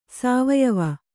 ♪ sāvayava